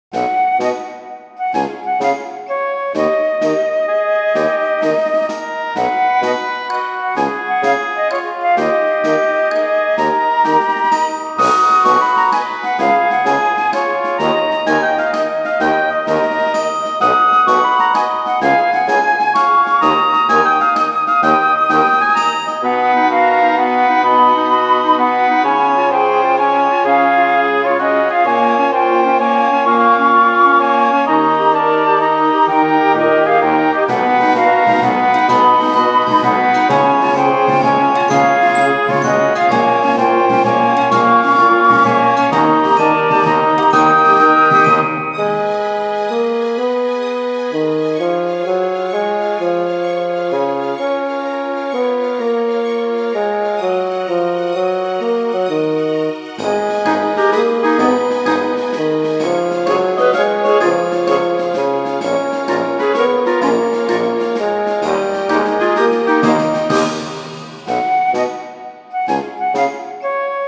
明るい にぎやか